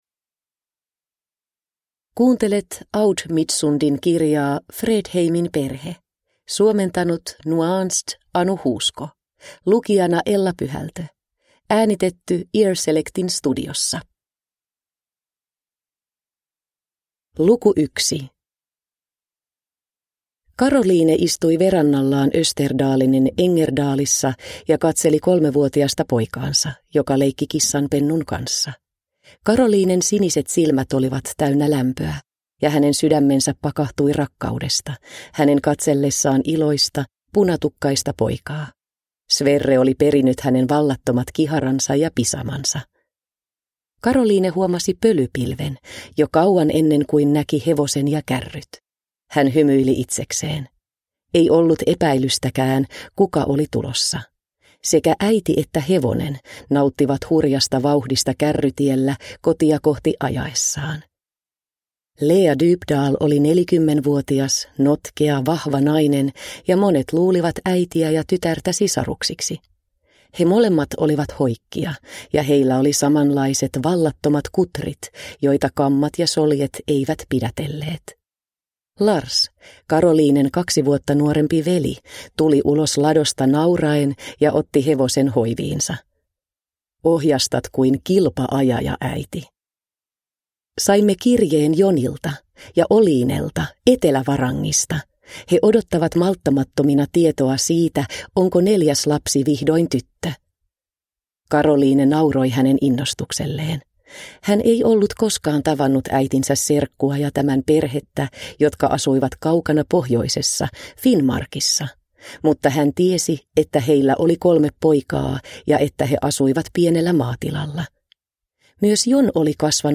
Fredheimin perhe – Ljudbok – Laddas ner